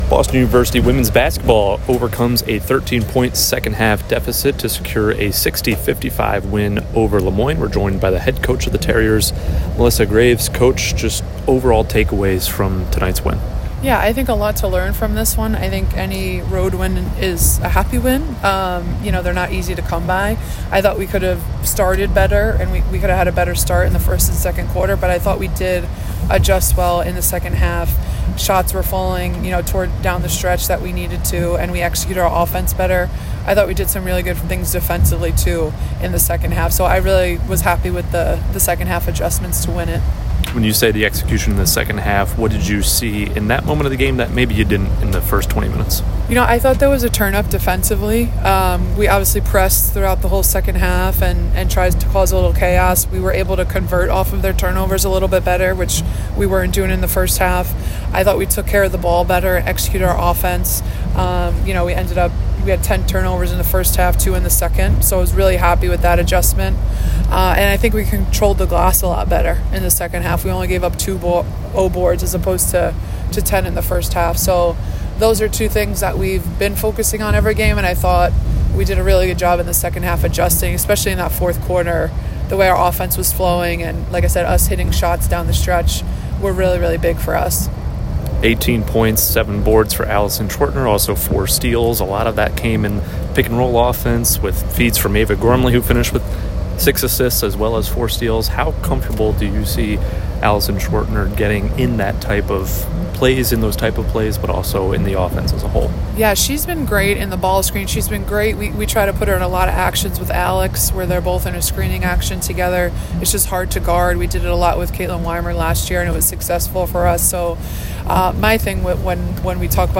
WBB_Le_Moyne_Postgame.mp3